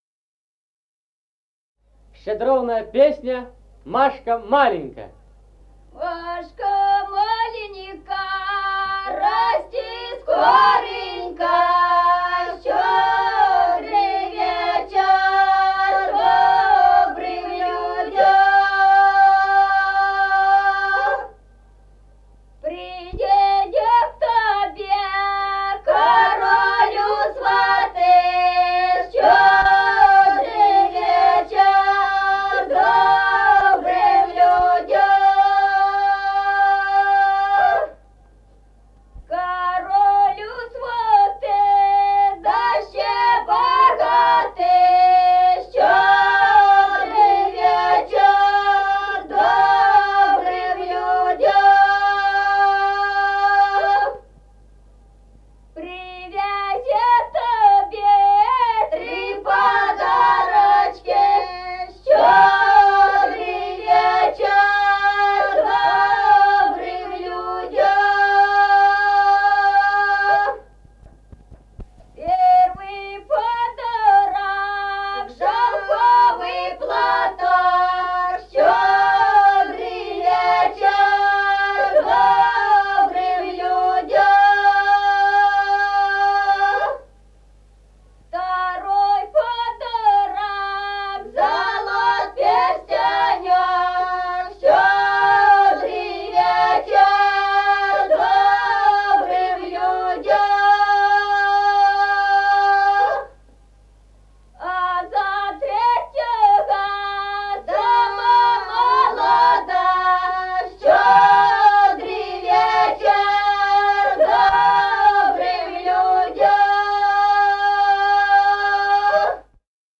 Народные песни Стародубского района «Машка маленька», новогодняя щедровная.
(подголосник)
(запев).
1953 г., с. Мишковка.